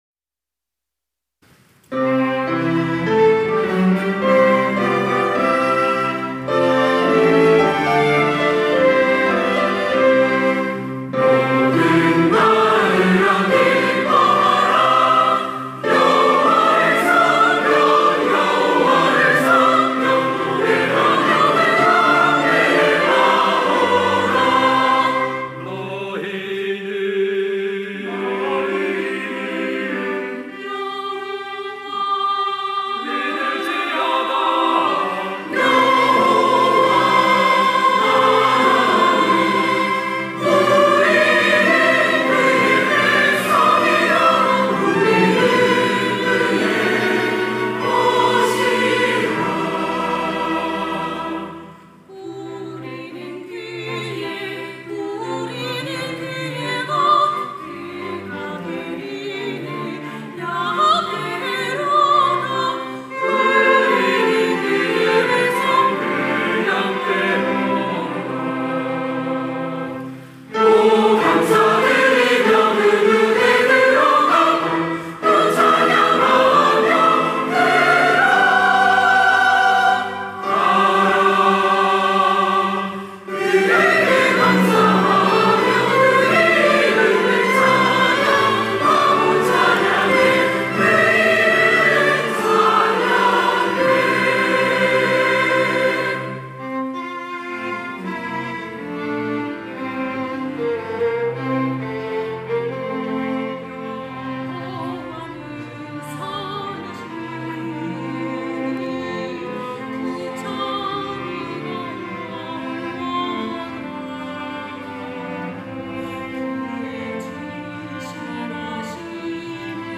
호산나(주일3부) - 하나님을 찬양하라
찬양대